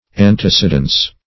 Antecedence \An`te*ced"ence\, n.